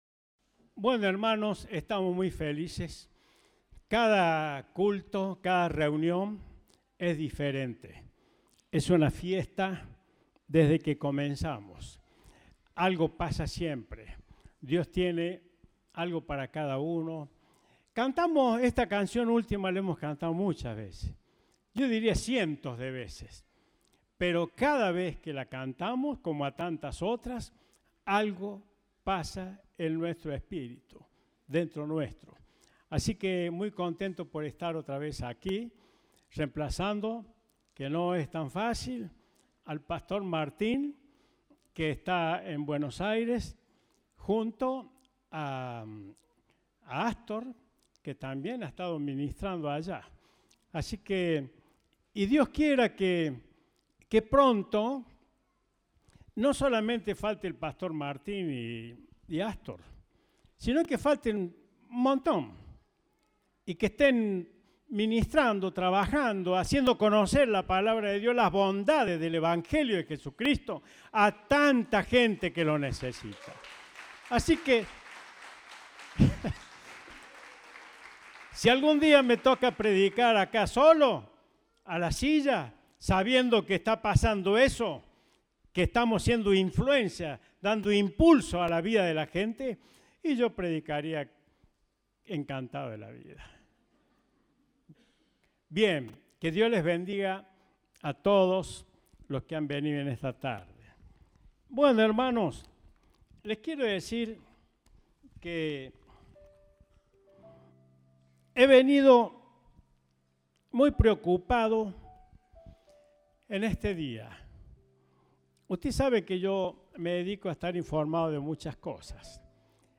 Compartimos el mensaje del Domingo 12 de Febrero de 2023